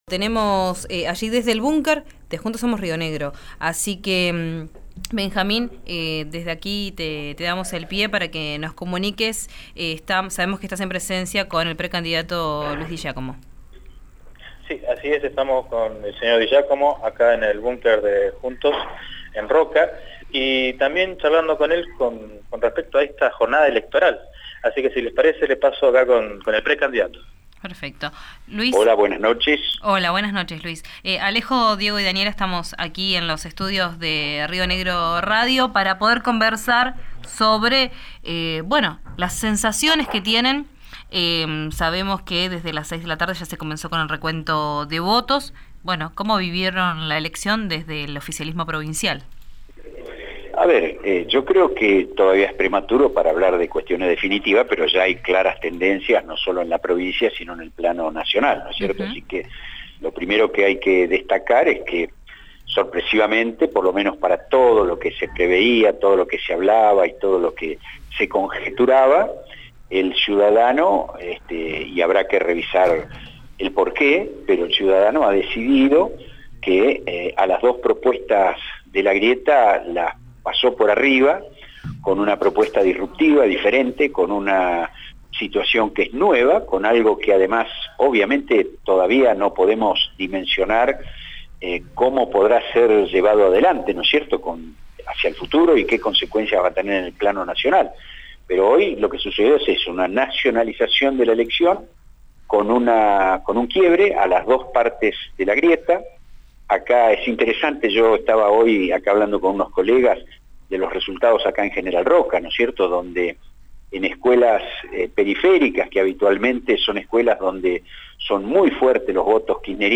El precandidato a diputado nacional Luis Di Giacomo por Juntos Somos Río Negro (JSRN) habló esta noche pasadas las 21 horas desde su bunker sobre calle Maipú en pleno centro de Roca. En medio de un clima de total tranquilidad por los resultados desfavorables para la fuerza oficialista provincial, brindó declaraciones a RÍO NEGRO RADIO.